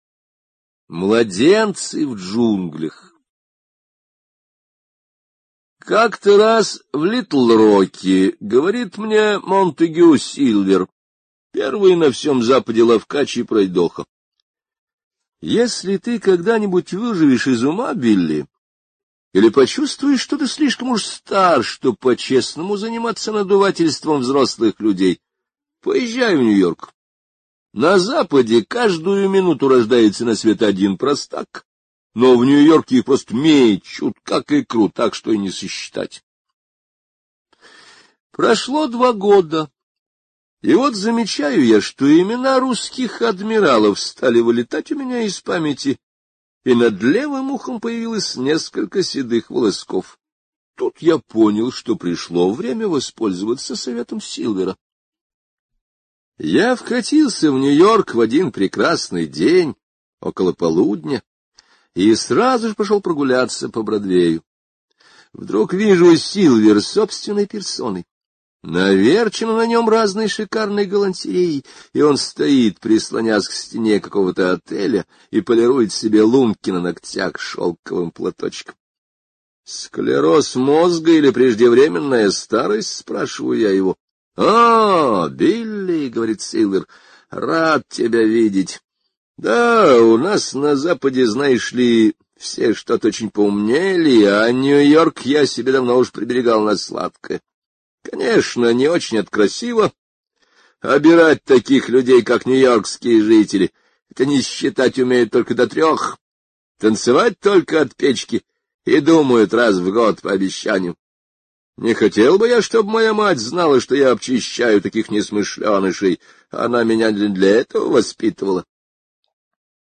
Младенцы в джунглях — слушать аудиосказку Генри О бесплатно онлайн